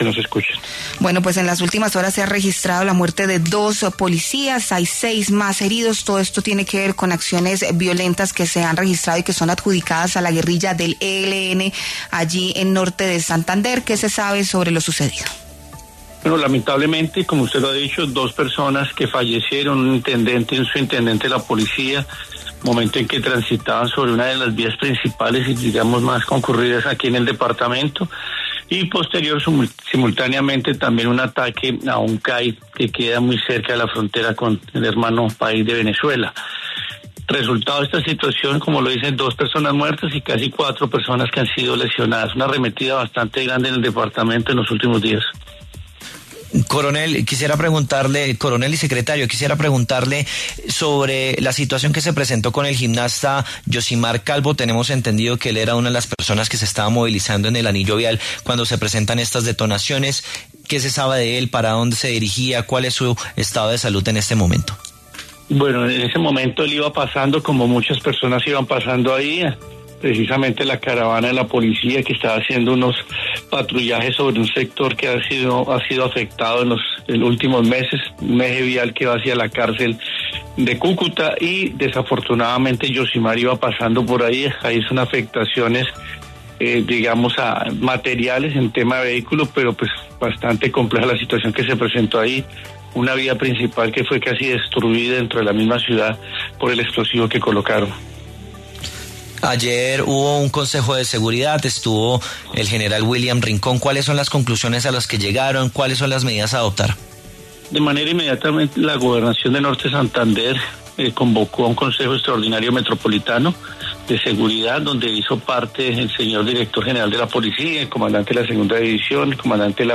Respecto a esto, el coronel George Quintero, secretario de Seguridad del Norte de Santander, habló en los micrófonos de La W y entregó detalles de este hecho.